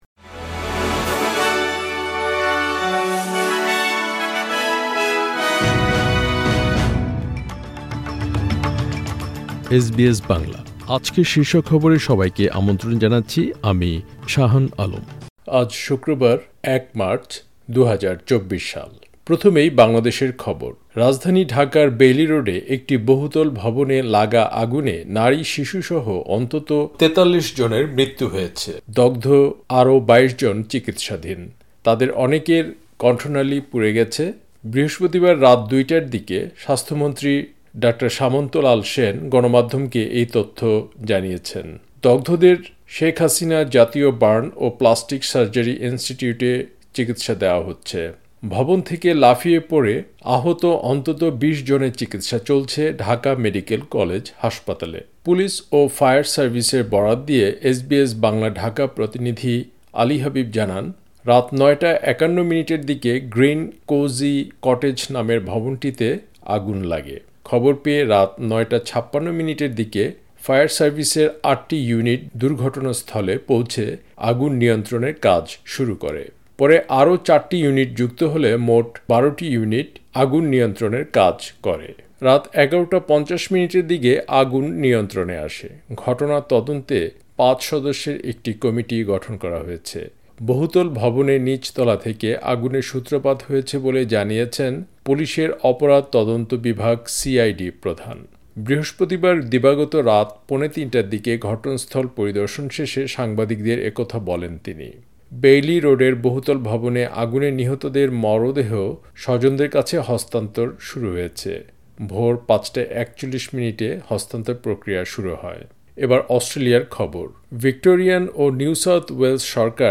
এসবিএস বাংলা শীর্ষ খবর: ১ মার্চ, ২০২৪